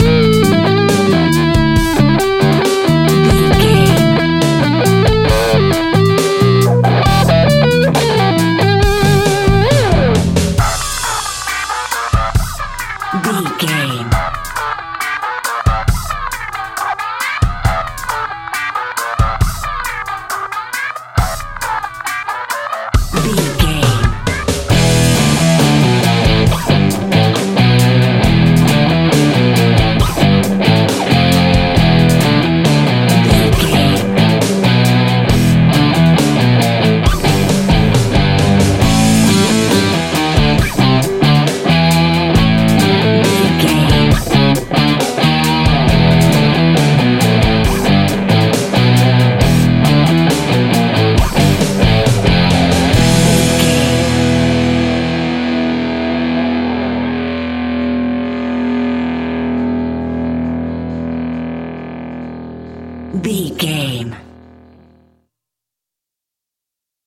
Epic / Action
Aeolian/Minor
hard rock
heavy metal
royalty free rock music
Heavy Metal Guitars
Metal Drums
Heavy Bass Guitars